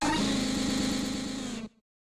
Cri de Deusolourdo dans Pokémon Écarlate et Violet.